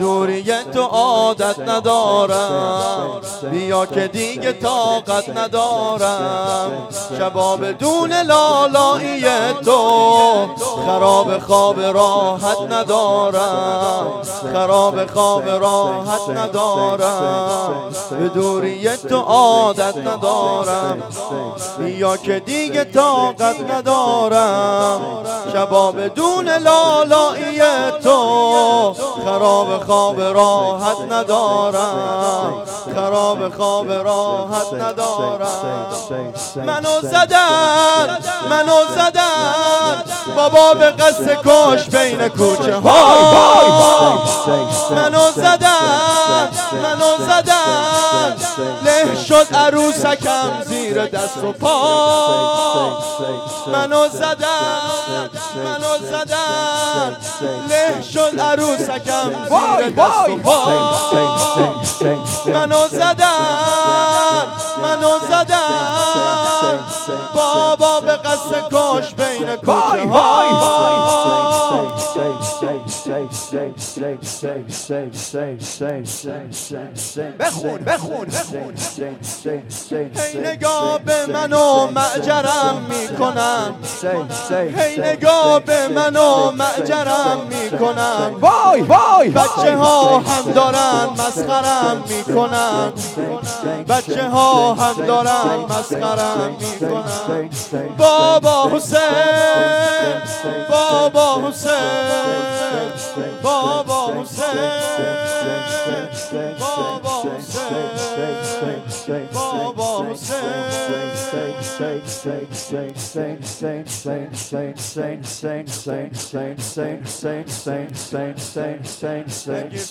شور
مراسم هفتگی